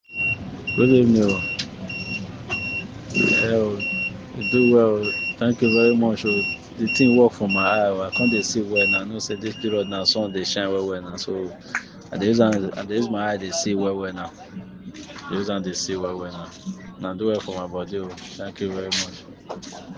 Verified Customer
Testimonial 4